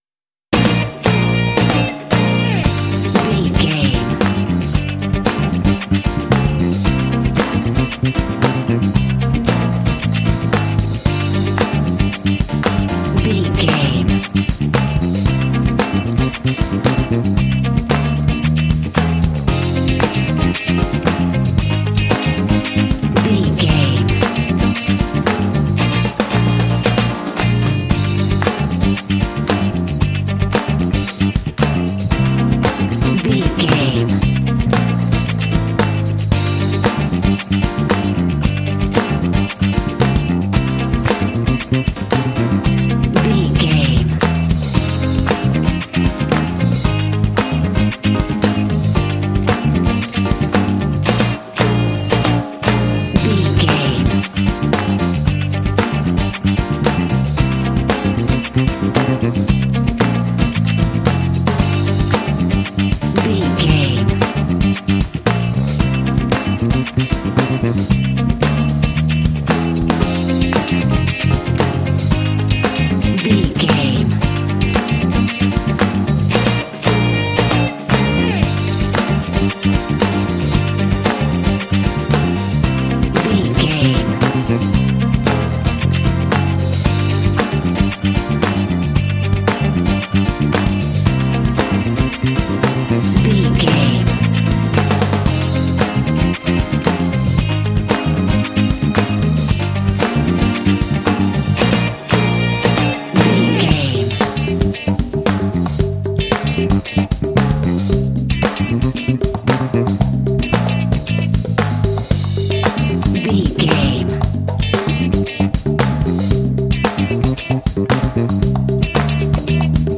Uplifting
Aeolian/Minor
funky
groovy
bright
lively
energetic
bass guitar
electric guitar
piano
drums
percussion
strings
Funk
jazz